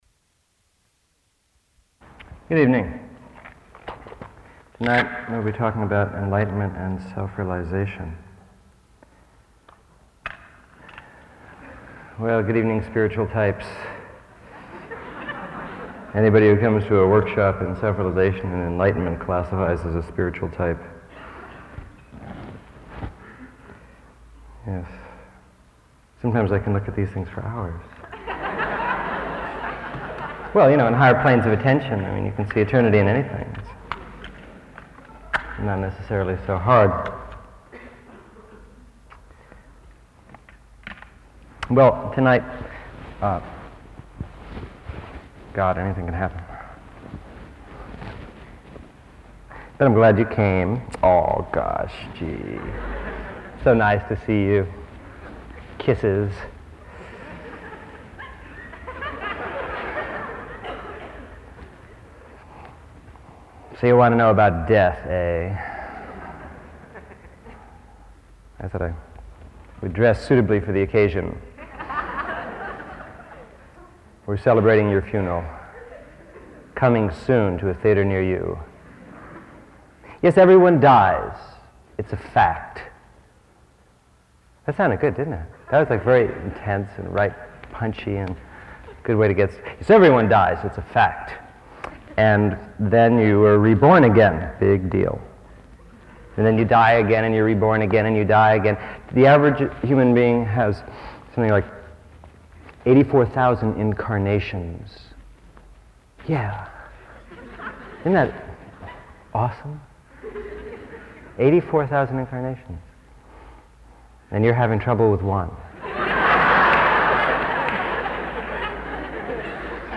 A Workshop